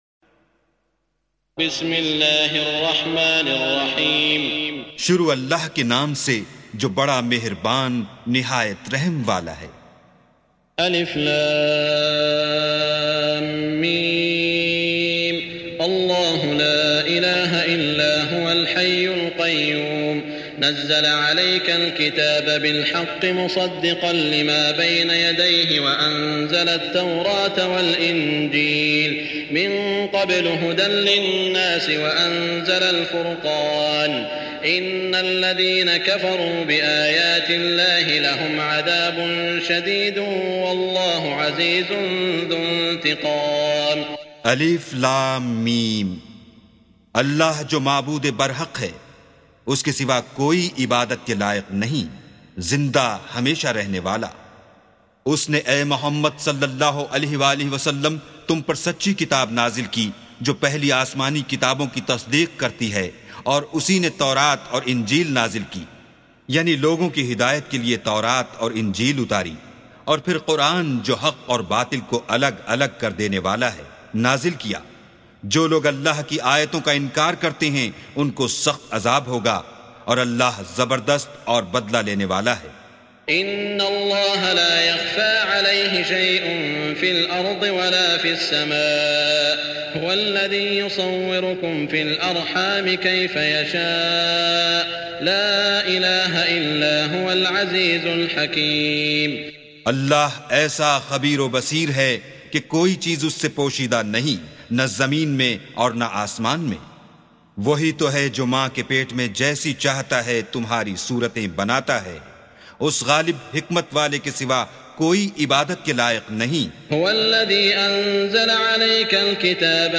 سُورَةُ ٓآلِ عِمۡرَانَ بصوت الشيخ السديس والشريم مترجم إلى الاردو